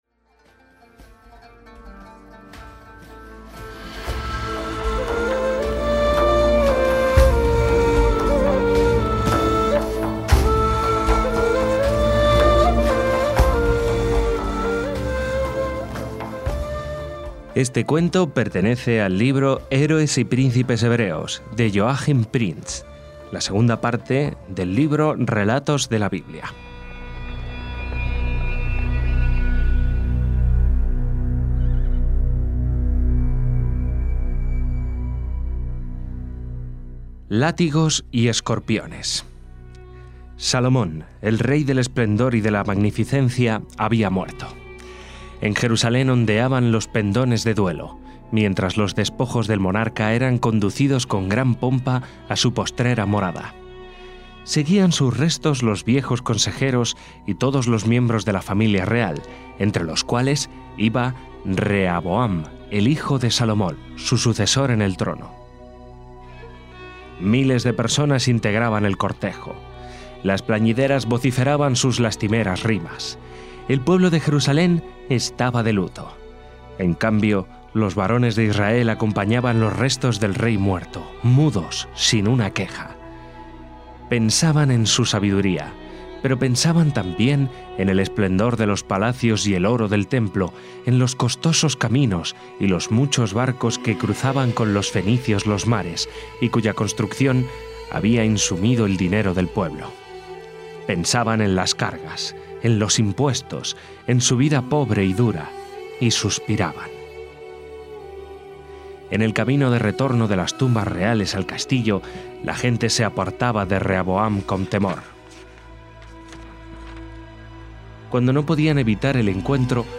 Y como aquella, responde a la lectura de algunos capítulos de un libro de Joachim Prinz, en este caso de título homónimo al de la sección, continuación del «Relatos de la Biblia». Y comenzamos con la historia de Rehaboam, hijo del rey Salomón